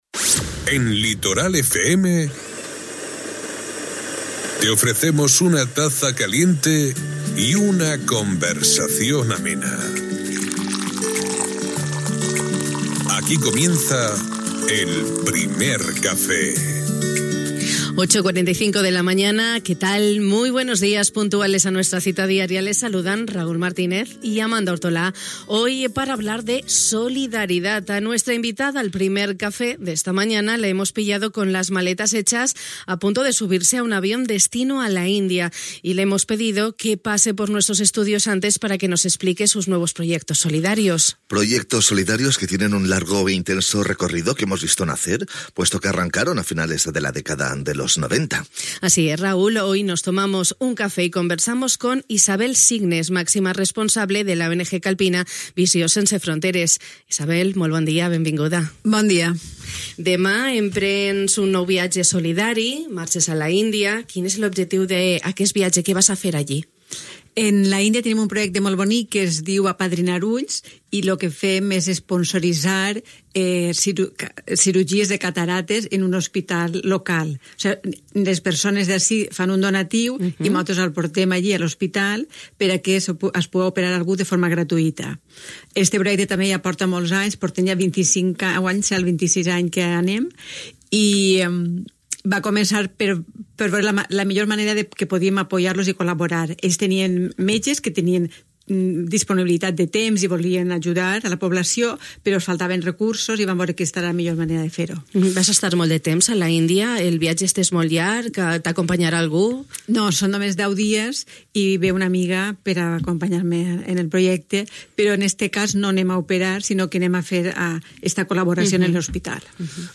A la nostra convidada al Primer Cafè de Ràdio Litoral l'hem enxampat amb les maletes fetes a punt de pujar a un avió amb destinació a l'Índia, però abans s'ha passat pels nostres estudis per explicar-nos els seus projectes solidaris.